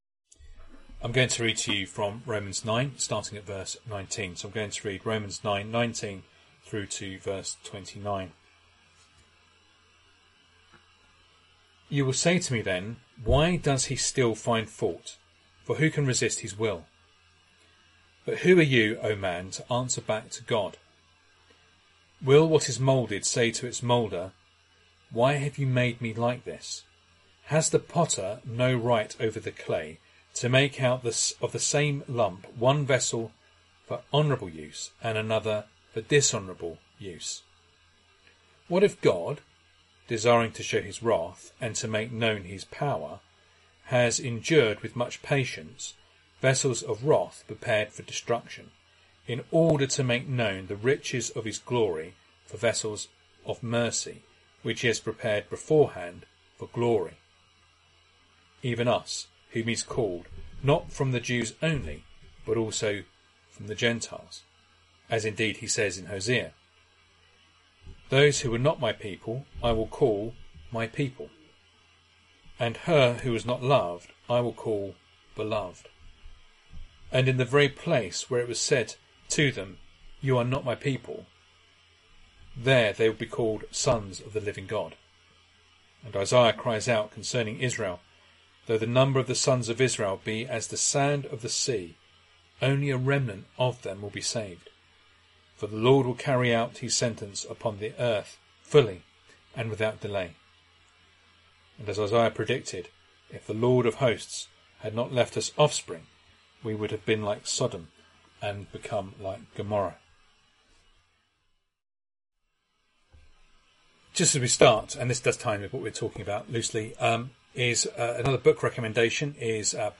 Romans 9:19-29 Service Type: Sunday Evening Reading and Sermon Audio